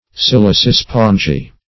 Meaning of silicispongiae. silicispongiae synonyms, pronunciation, spelling and more from Free Dictionary.
Search Result for " silicispongiae" : The Collaborative International Dictionary of English v.0.48: Silicispongiae \Sil`i*ci*spon"gi*ae\, n. pl.